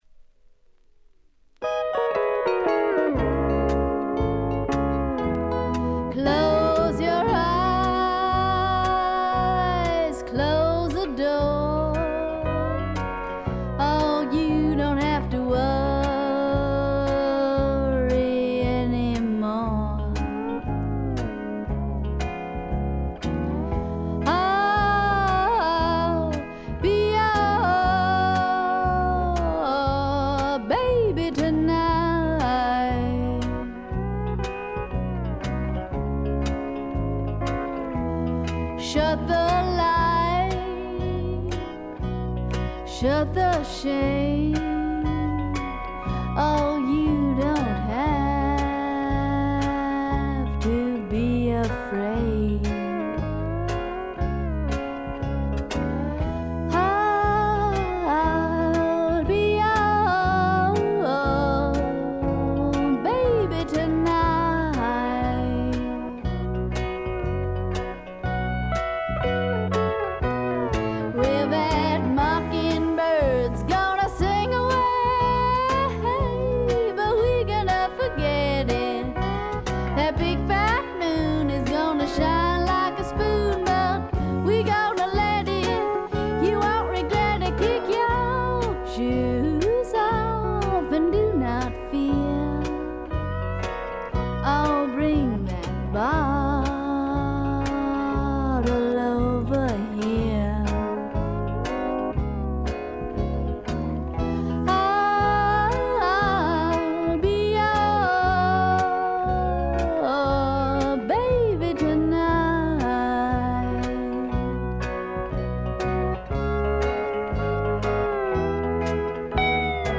軽微なチリプチ少し。
わざわざ言うまでもなく米国東海岸を代表するグッドタイムミュージックの超絶名盤です。
試聴曲は現品からの取り込み音源です。